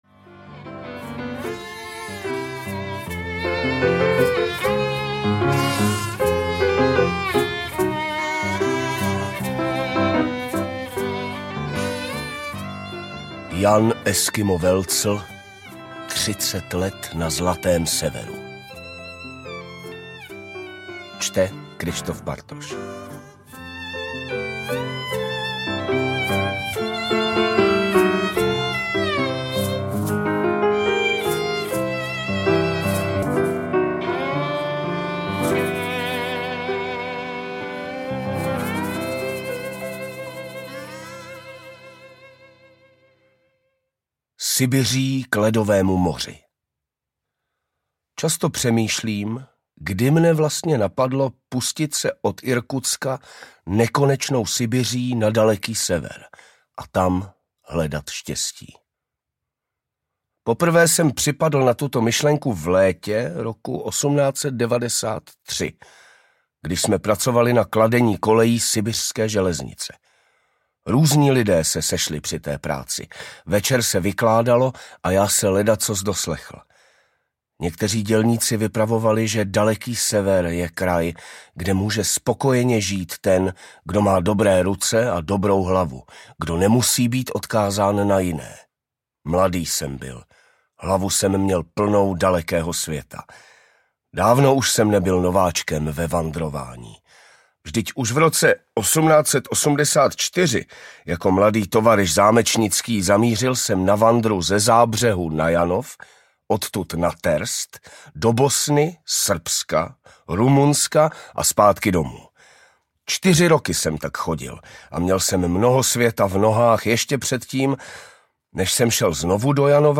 Třicet let na zlatém severu audiokniha
Ukázka z knihy